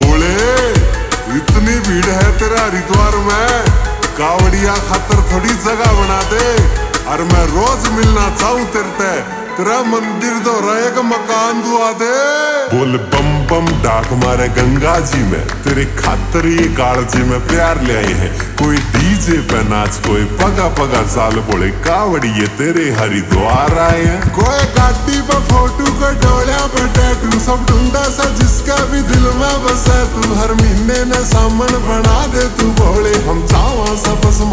Haryanvi Songs
Slow Reverb Version
• Simple and Lofi sound
• Crisp and clear sound